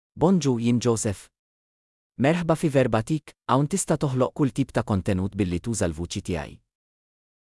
MaleMaltese (Malta)
Joseph — Male Maltese AI voice
Joseph is a male AI voice for Maltese (Malta).
Voice sample
Listen to Joseph's male Maltese voice.